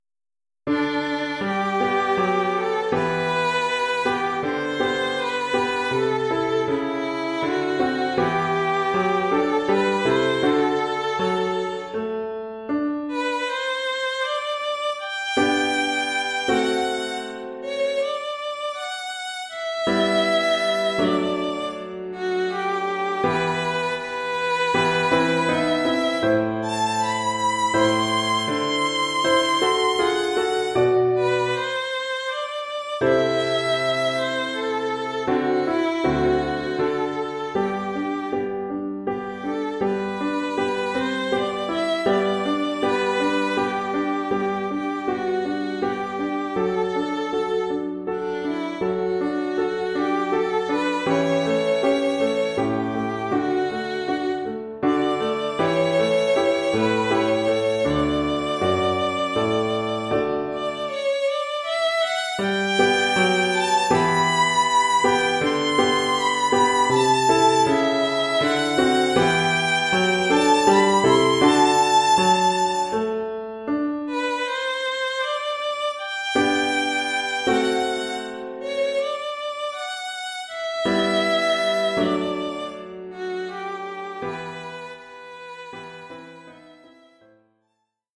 Oeuvre pour violon et piano.